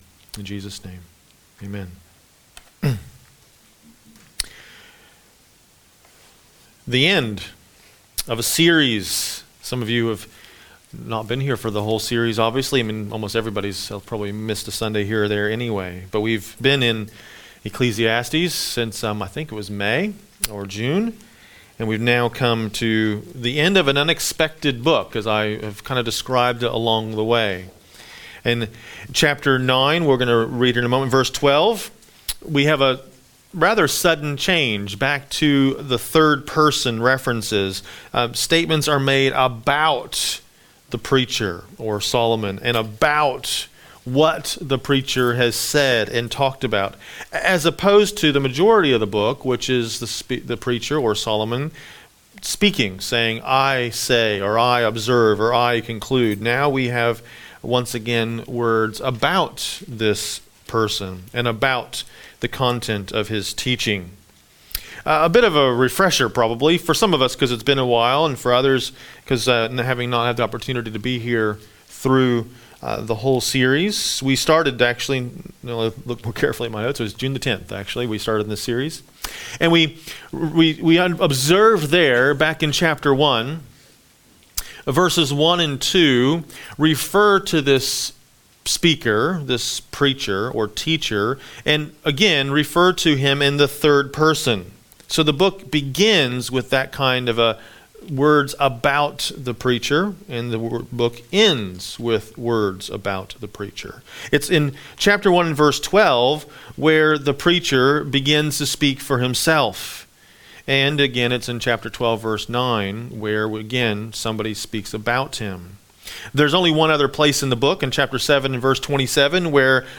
A message from the series "Mark."